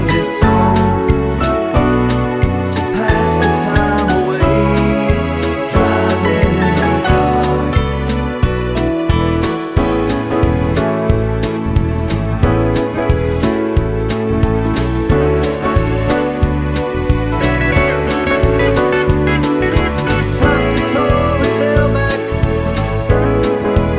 no Backing Vocals Soft Rock 4:06 Buy £1.50